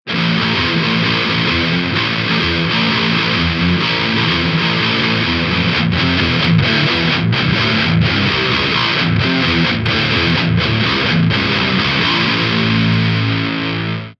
Вложения Soundcheck_Impulse_no_drums.mp3 Soundcheck_Impulse_no_drums.mp3 444,1 KB · Просмотры: 183